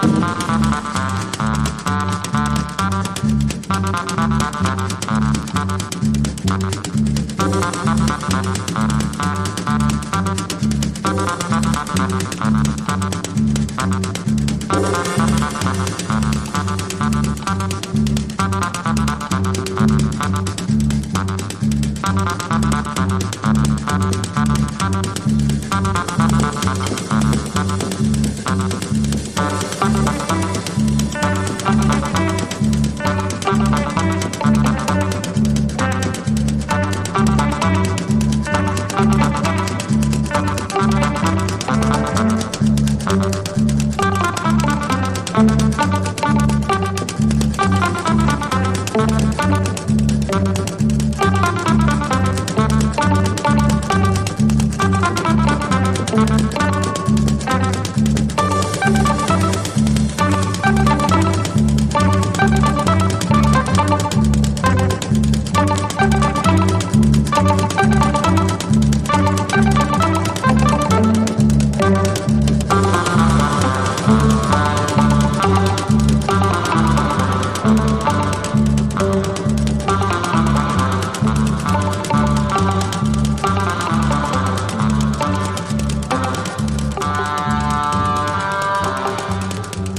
ストレンジでサイケデリックなアウトサイダー・ミュージック金字塔！